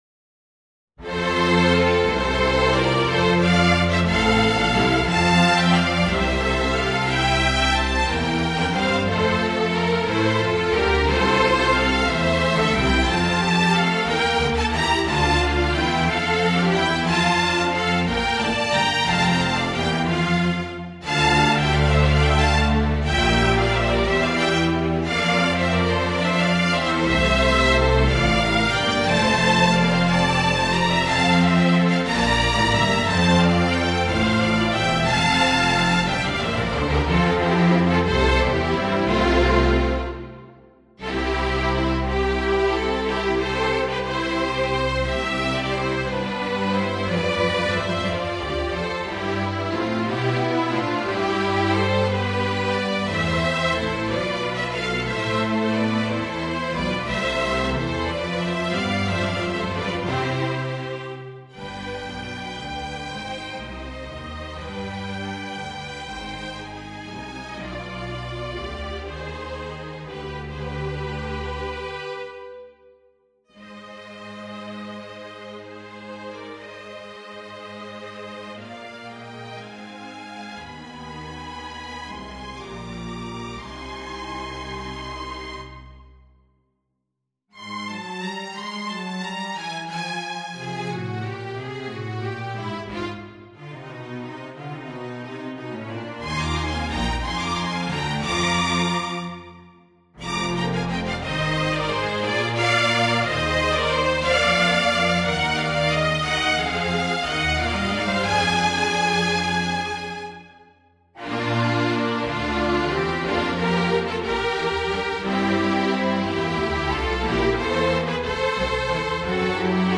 Mock-ups generated by NotePerformer can be heard below (to listen, click on the white arrowhead on the left of the bar).
A tonal and melodic suite for solo flute and strings made up of pieces inspired by Chamber Music, an early collection of poetry by James Joyce (1907).